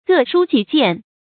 各抒己見 注音： ㄍㄜˋ ㄕㄨ ㄐㄧˇ ㄐㄧㄢˋ 讀音讀法： 意思解釋： 抒：抒發，發表。